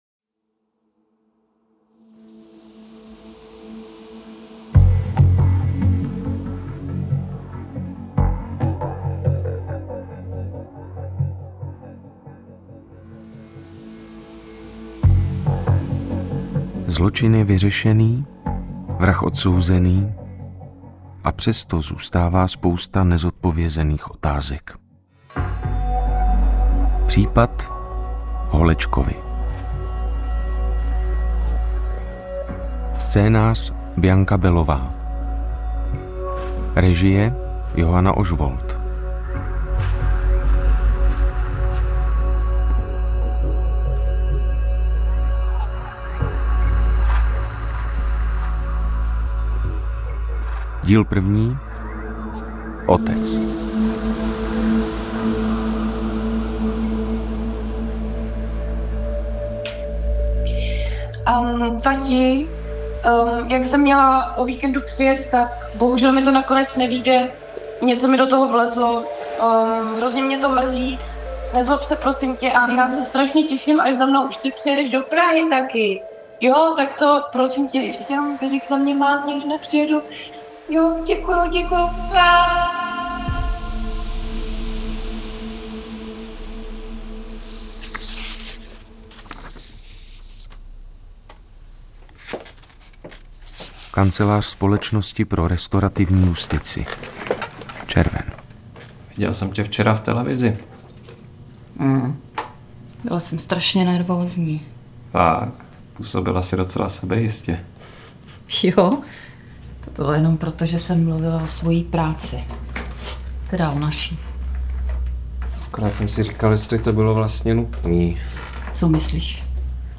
První hraná série o restorativní justici.